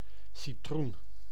Ääntäminen
Synonyymit jaune (slangi) tête Ääntäminen France: IPA: [dy si.tʁɔ̃] Tuntematon aksentti: IPA: /sit.ʁɔ̃/ Haettu sana löytyi näillä lähdekielillä: ranska Käännös Konteksti Ääninäyte Substantiivit 1. citroen {f} kasvitiede 2.